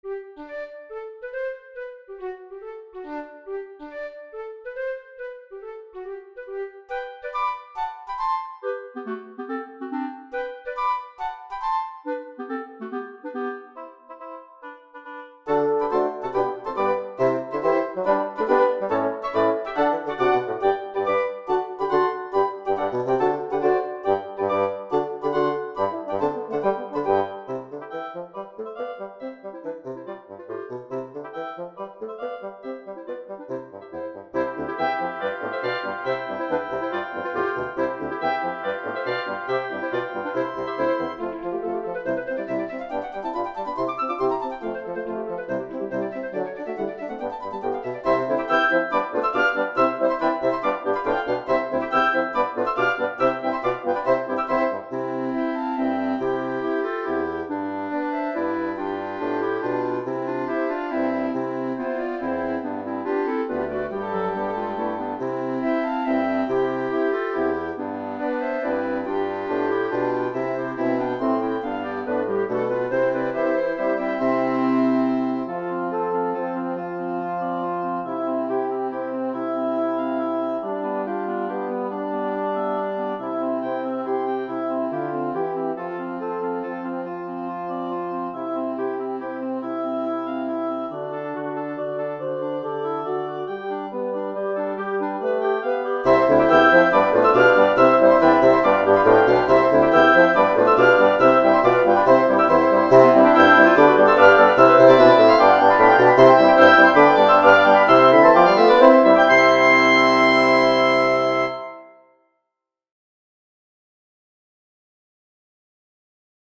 Divertimento For Double Woodwind Quintet
I know I've sent this in before, but I want you to hear this version with additional harmony and realistic instrumental sounds. I redid this with the FINALE composition program.
It is very traditional in theory structure and chord progressions but very countrapuntal.
CHAMBER MUSIC